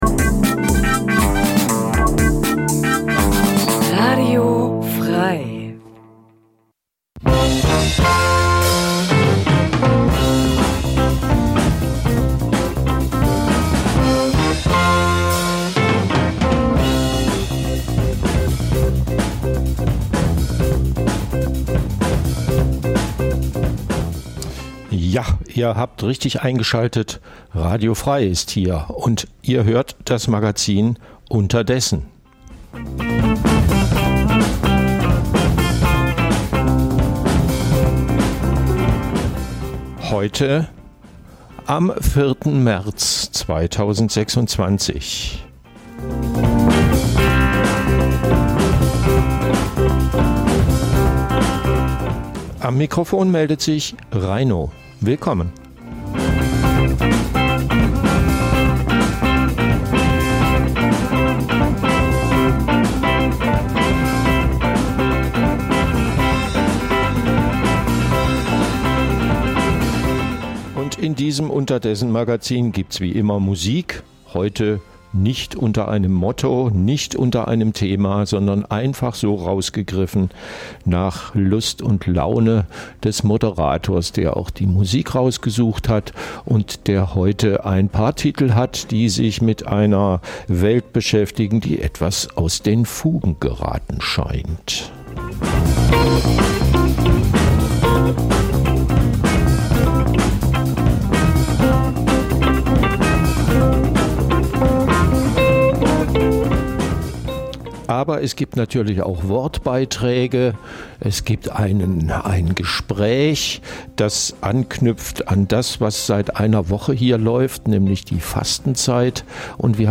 Das tagesaktuelle Livemagazin sendet ab 2024 montags bis freitags 9-11 Uhr.